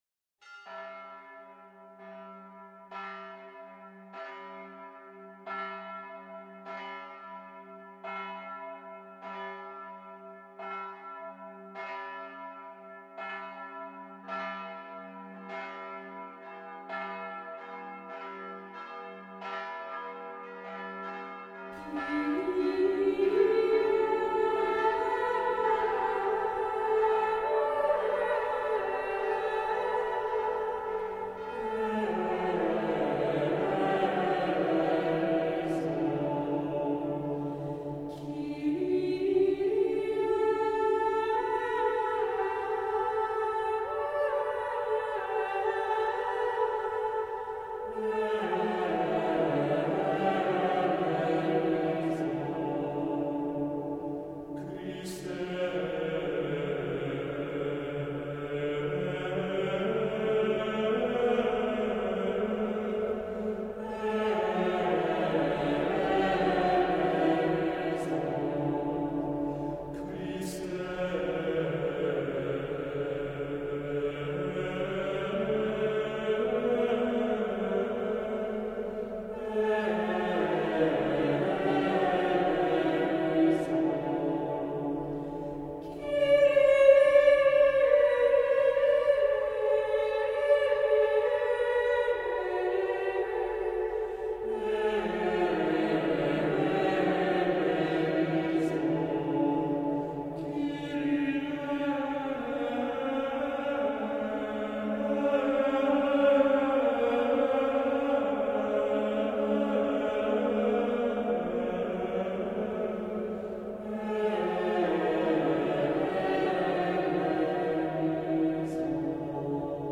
Voicing: Unison; Assembly